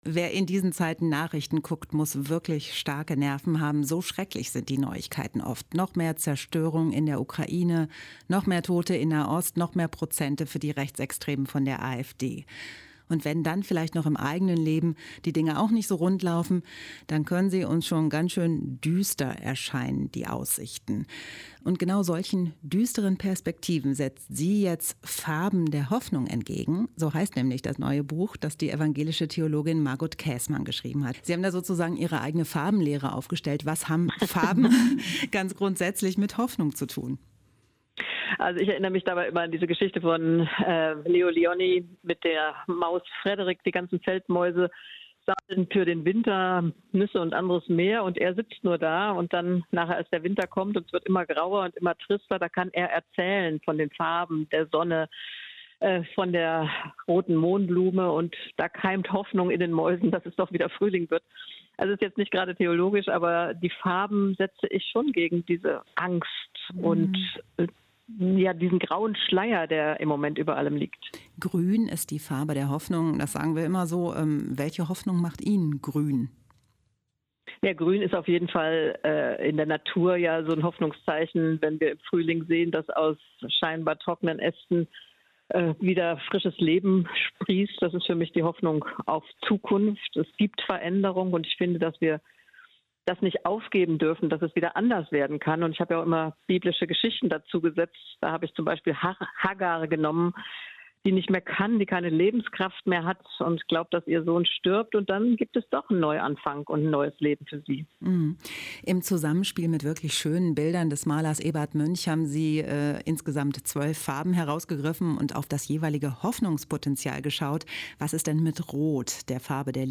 Ein Interview mit Margot Käßmann (Evangelische Theologin)